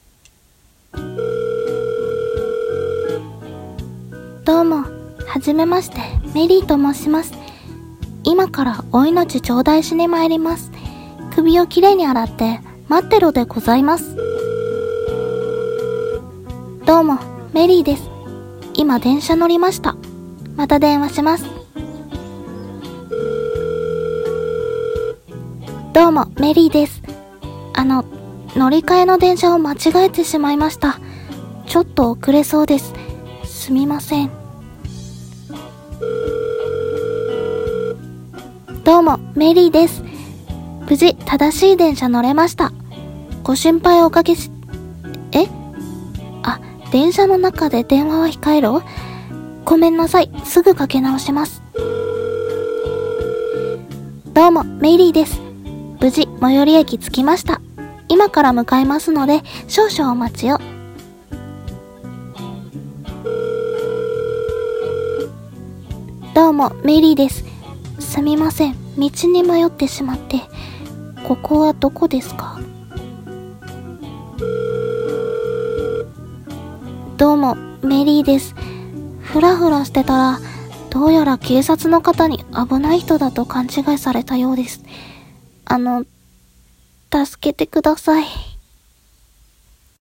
】【ギャグ声劇台本】どうも、メリーです。